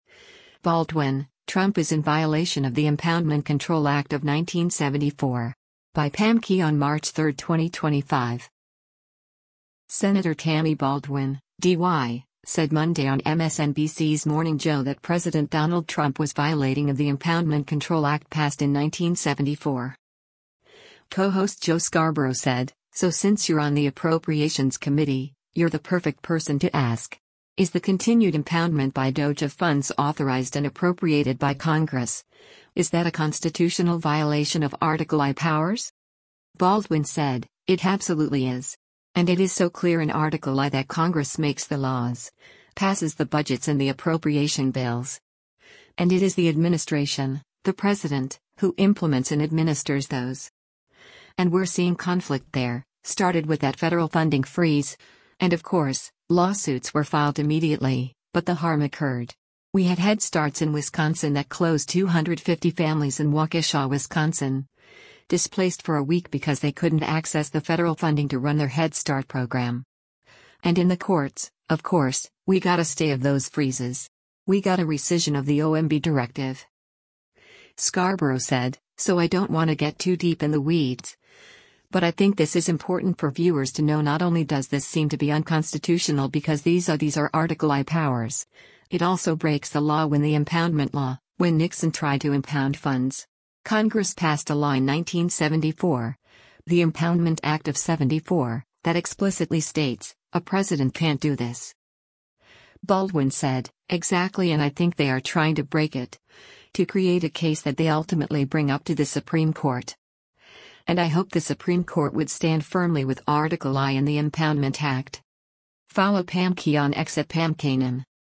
Senator Tammy Baldwin (D-WI) said Monday on MSNBC’s “Morning Joe” that President Donald Trump was violating of The Impoundment Control Act passed in 1974.